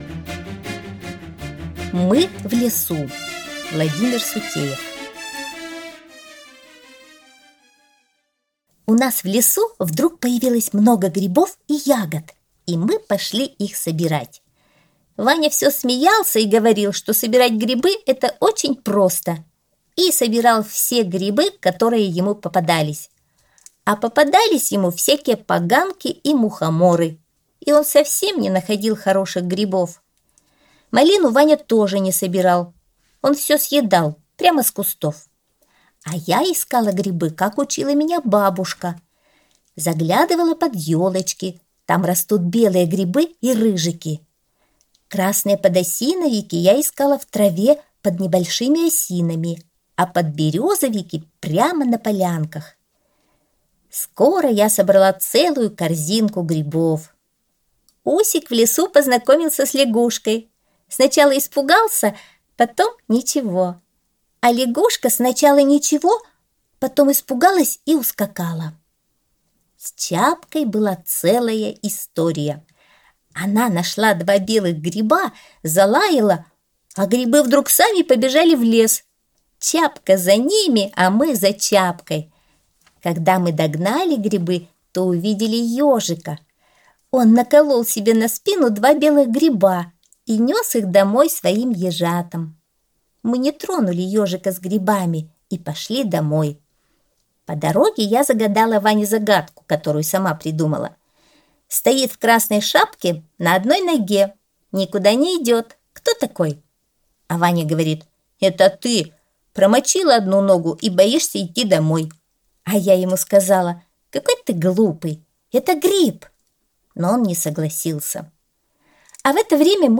Аудиосказка «Мы в лесу»